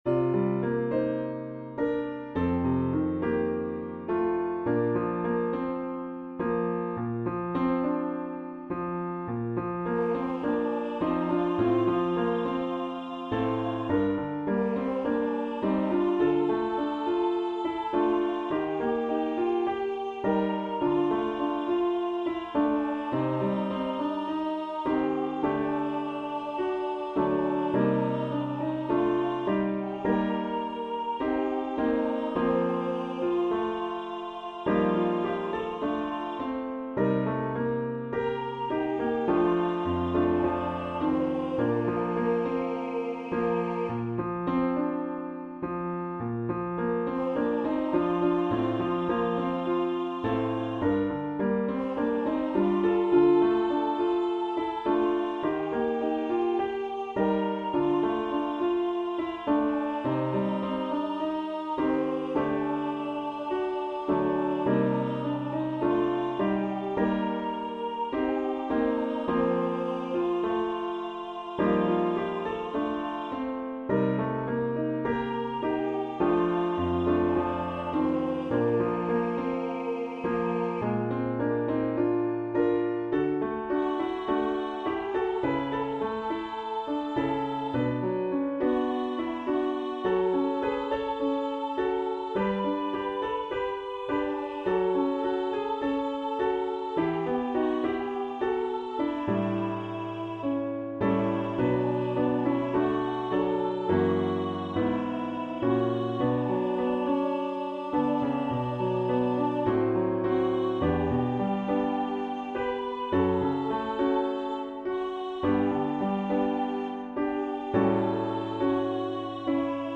Choose Medium or Low voice options. 2 versions on this page.